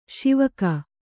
♪ Śivaka